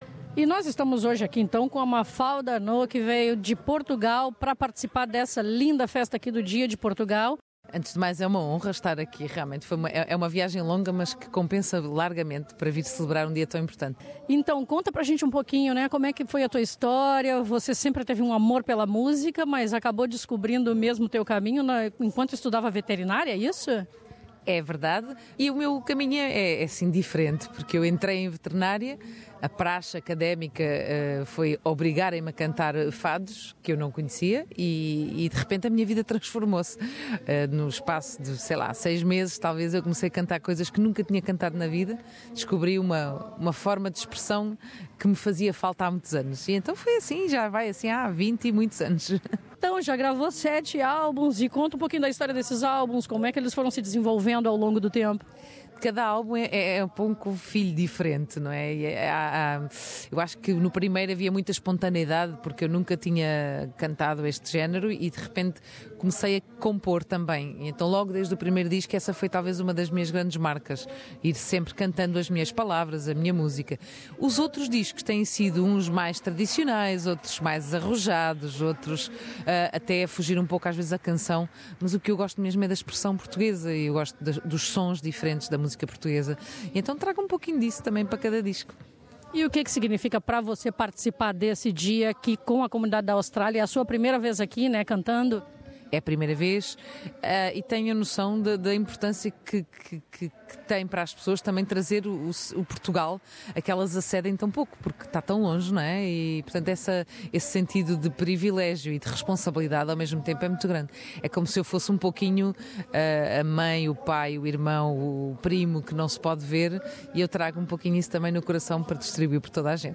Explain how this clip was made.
In this exclusive interview, she talks about her music and the honour to be part of the Portugal Day celebrations in Sydney.